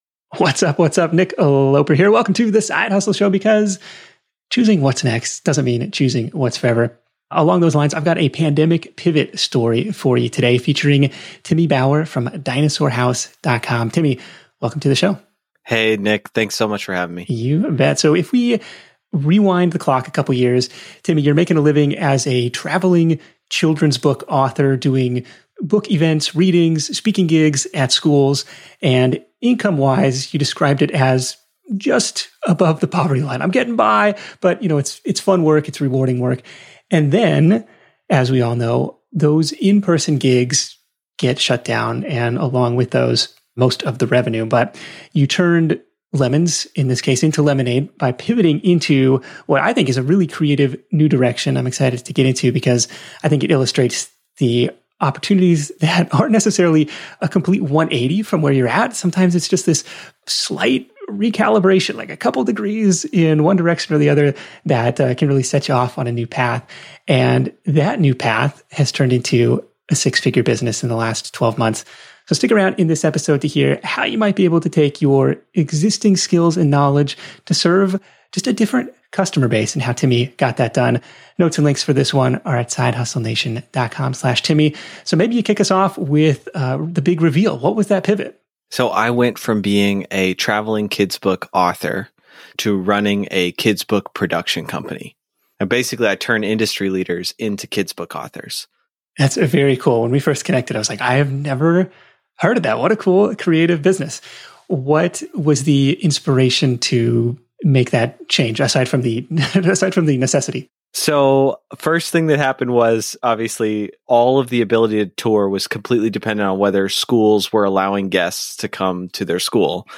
Tune in to The Side Hustle Show interview to hear